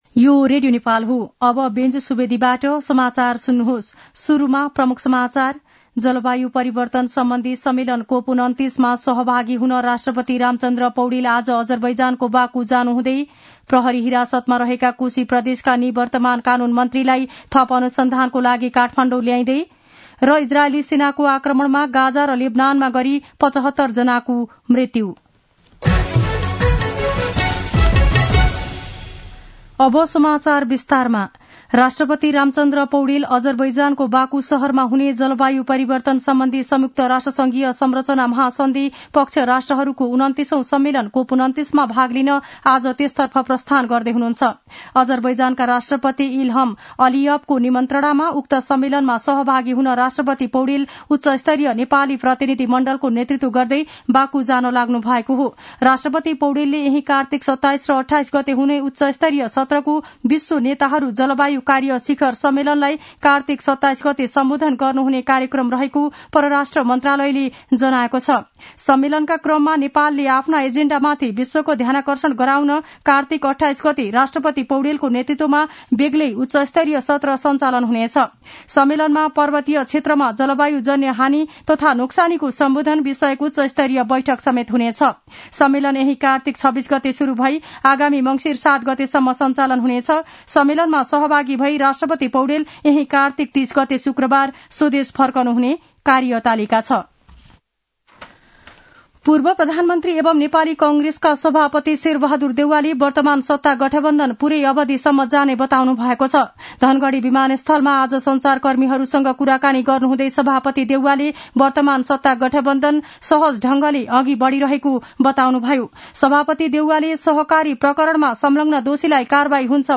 दिउँसो ३ बजेको नेपाली समाचार : २६ कार्तिक , २०८१
3-pm-news-2.mp3